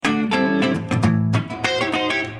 Funk guitars soundbank 1
Guitare loop - funk 32